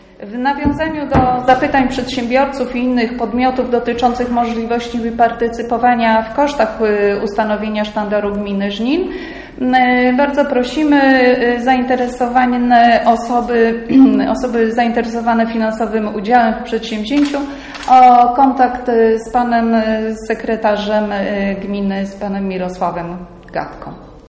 mówiła wiceburmistrz Halina Rosiak.